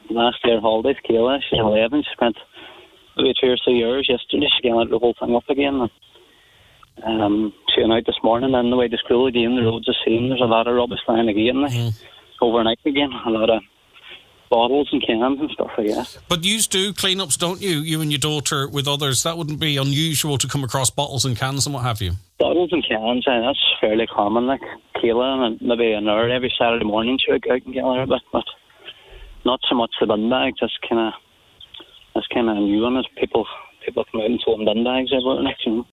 on this morning’s Nine ’til Noon Show: